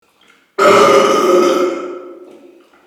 Loud Monster Burp - Botón de Efecto Sonoro